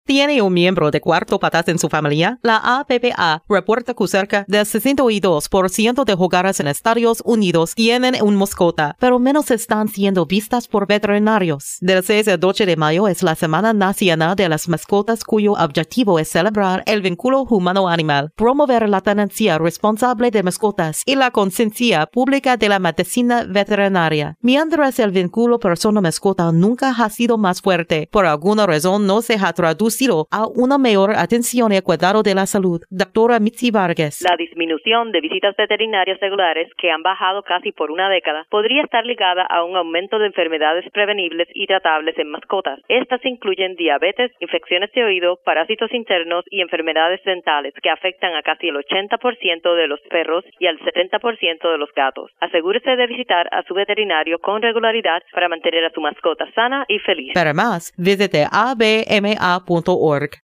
May 4, 2012Posted in: Audio News Release